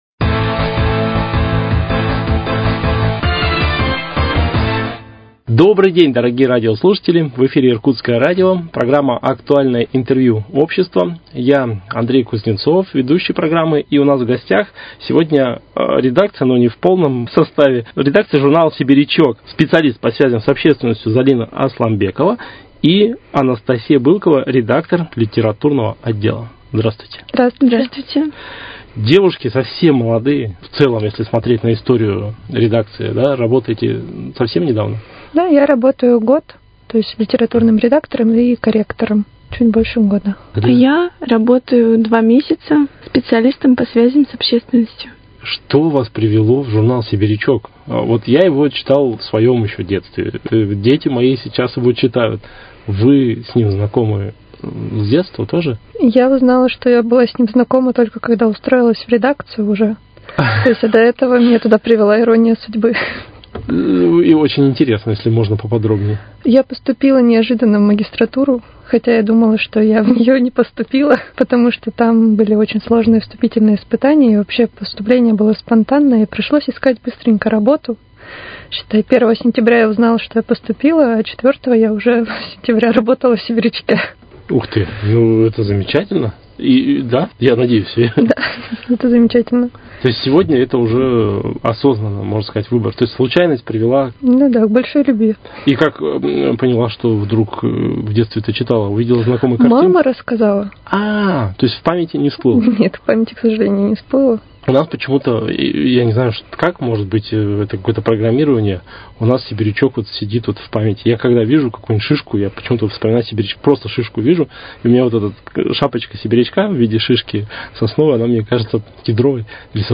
Актуальное интервью: Беседа с представителями редакции журнала «Сибирячок»